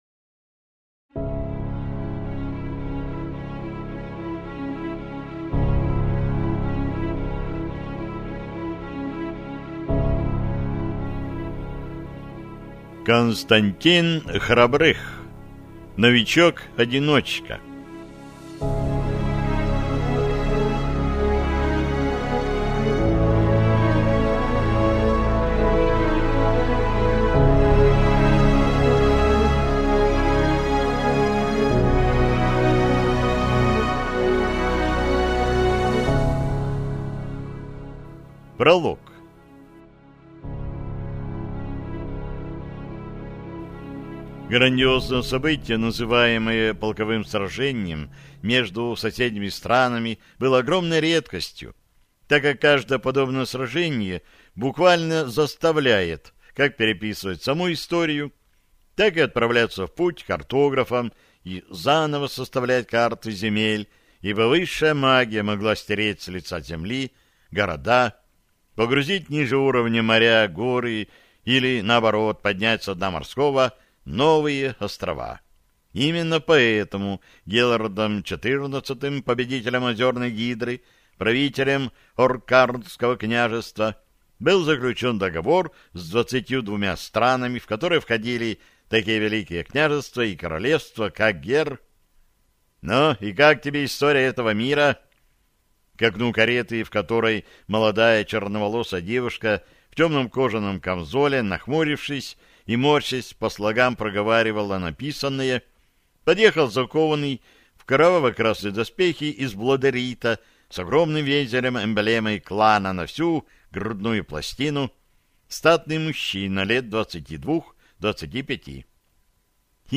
Аудиокнига Новичок-одиночка | Библиотека аудиокниг